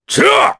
Kaulah-Vox_Attack2_jp.wav